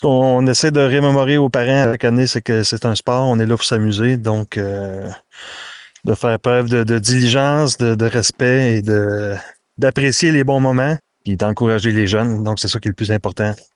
C’est ce qu’il a rappelé en entrevue.